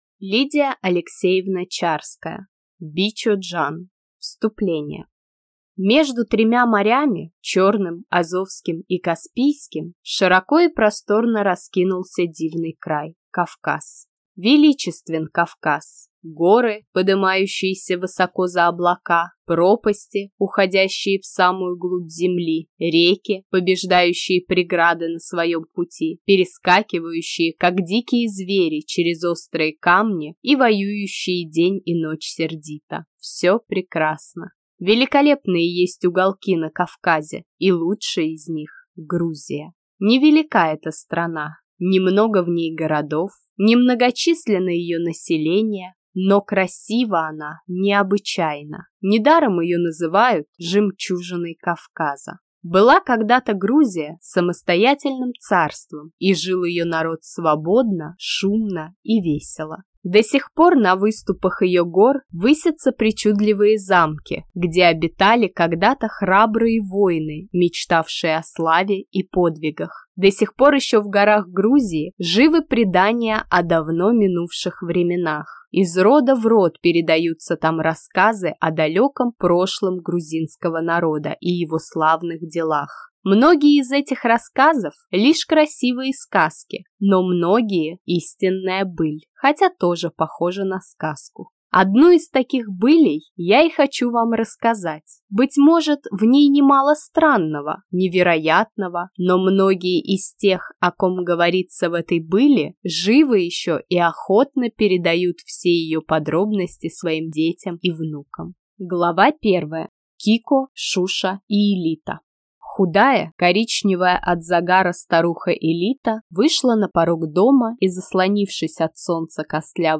Аудиокнига Бичо-Джан | Библиотека аудиокниг